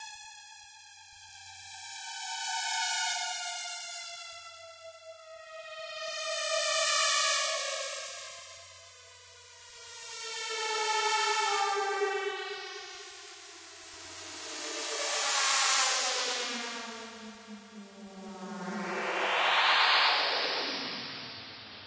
Tag: 机械 电子 机械 未来 航天器 外星人 空间 科幻 噪音